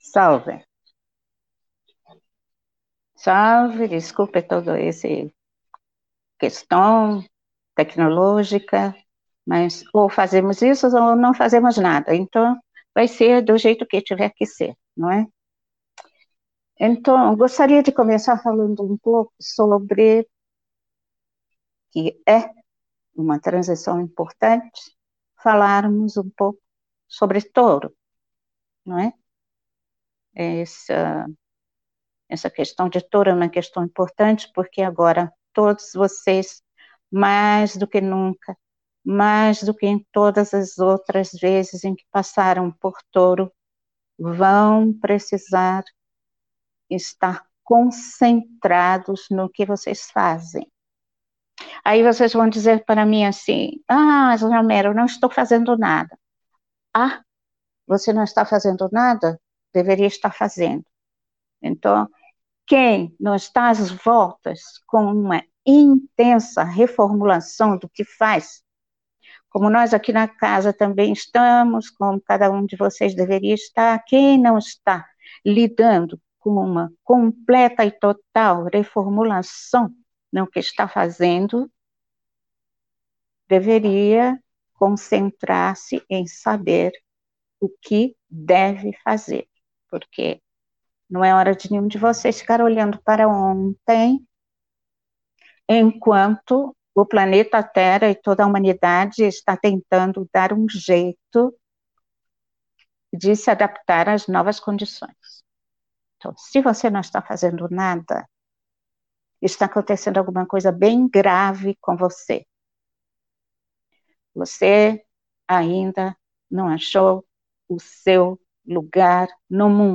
Palestra Canalizada